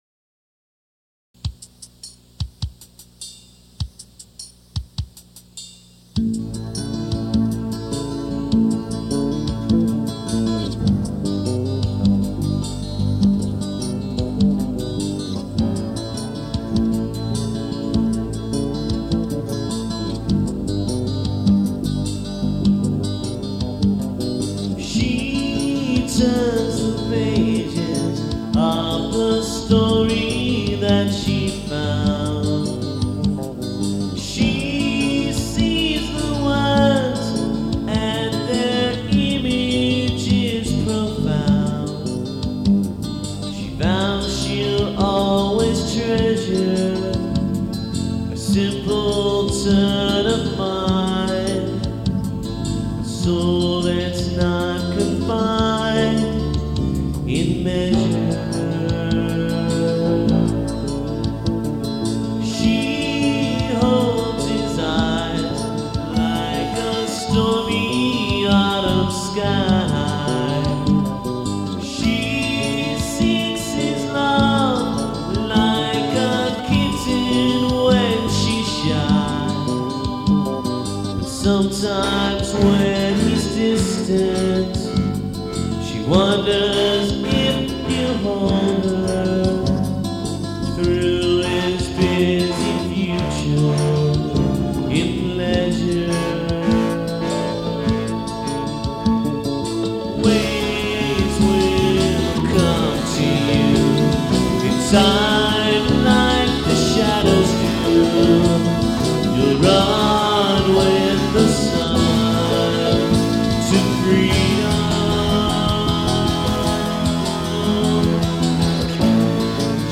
Vox, Guitfiddle, Bass, Keys, Lame Synth Drums